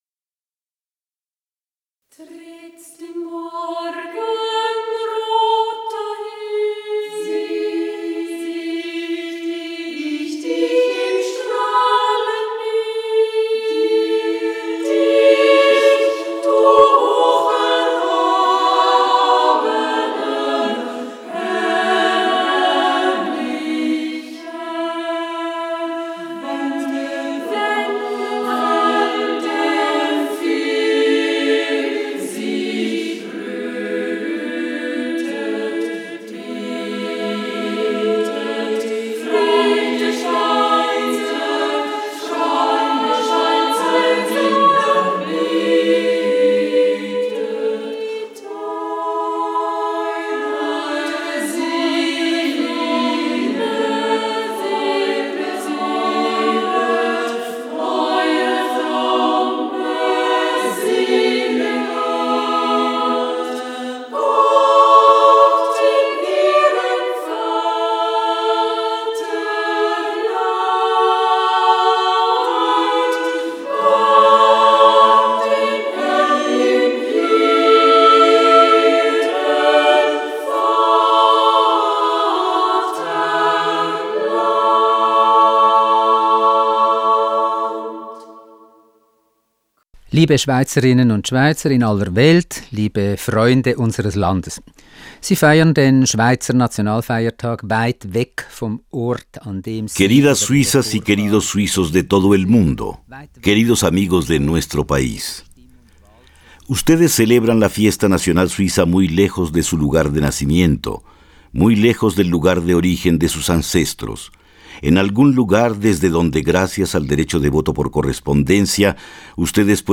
Discurso del presidente de la Confederación Moritz Leuenberger a los suizos del exterior.